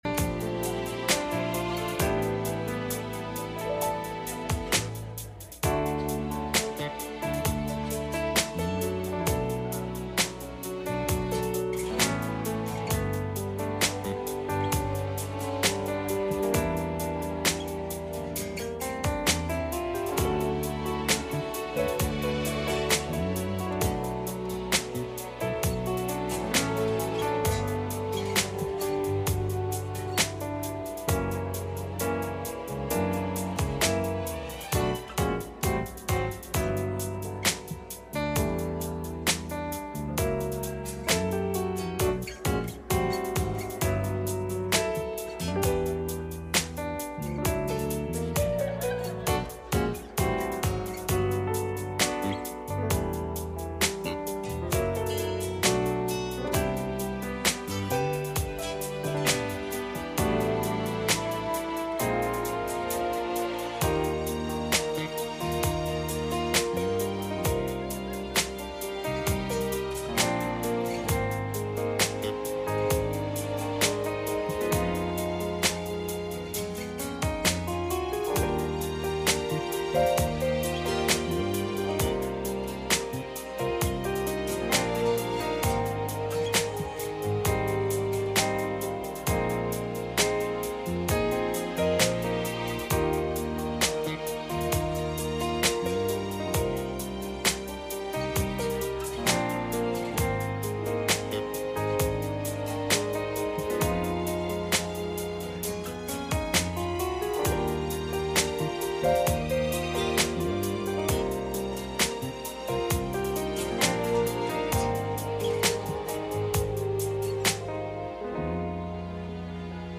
Isaiah 42:16 Service Type: Sunday Morning « Change